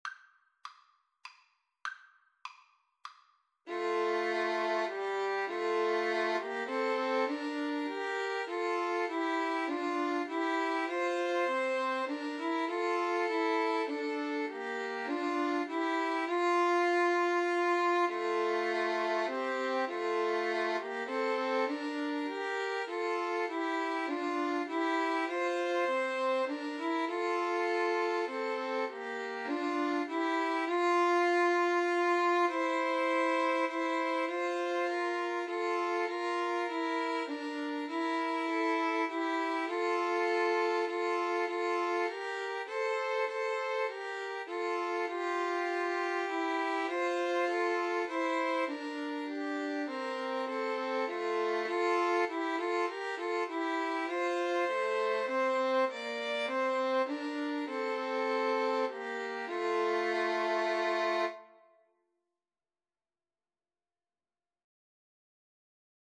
3/4 (View more 3/4 Music)
Classical (View more Classical 2-Violins-Cello Music)